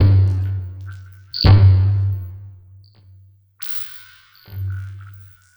Abstract Rhythm 03.wav